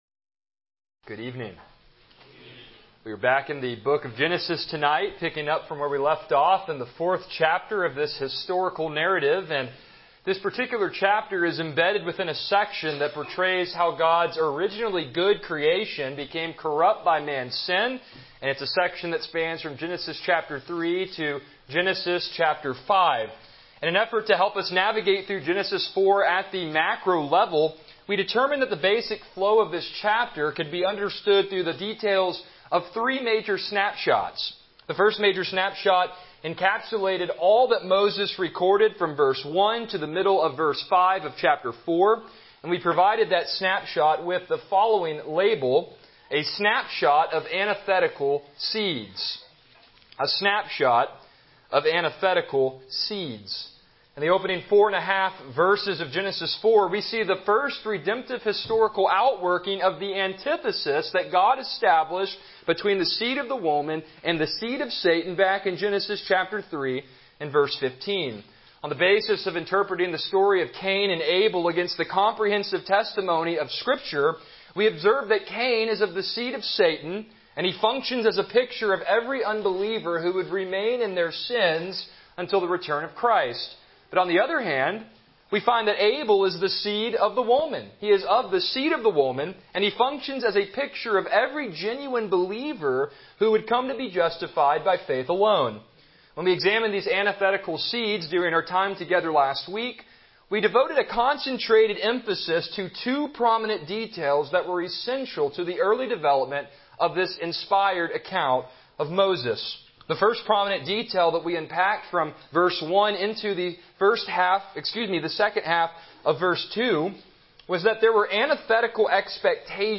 Service Type: Evening Worship